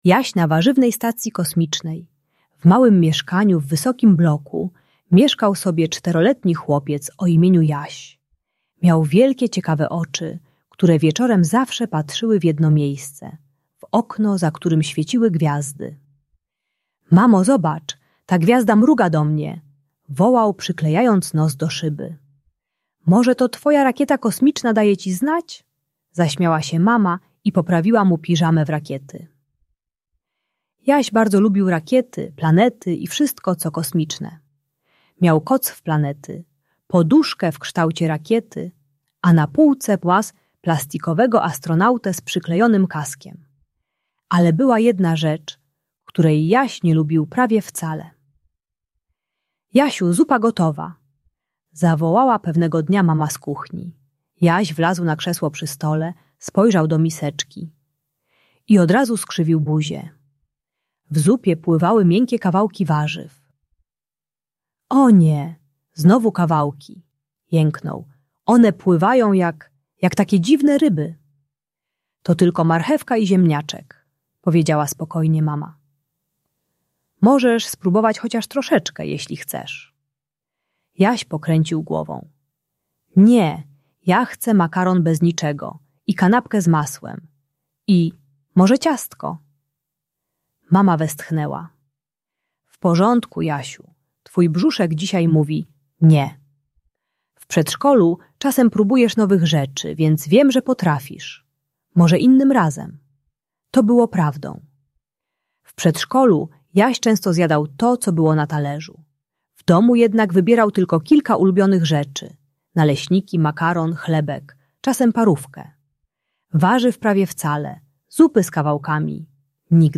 Jaś na Warzywnej Stacji Kosmicznej - Problemy z jedzeniem | Audiobajka